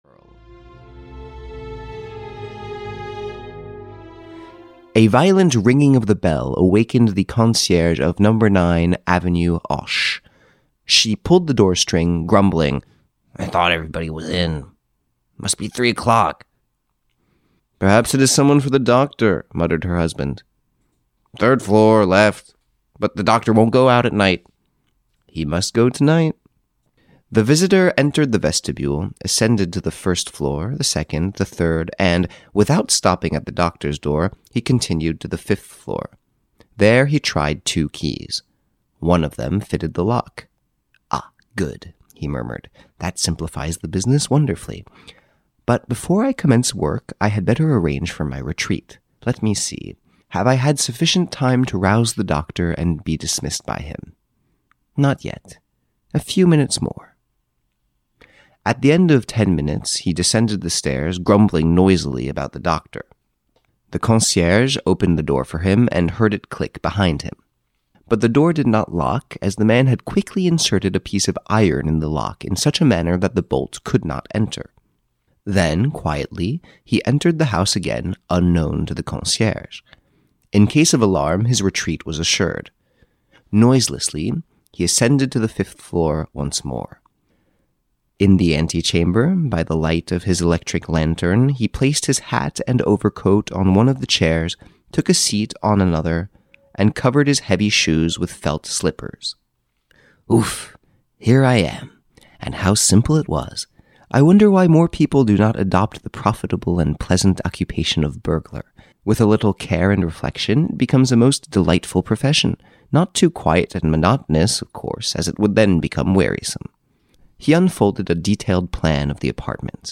The Black Pearl, the Adventures of Arsene Lupin the Gentleman Burglar (EN) audiokniha
Ukázka z knihy